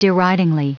Prononciation du mot deridingly en anglais (fichier audio)
Prononciation du mot : deridingly
deridingly.wav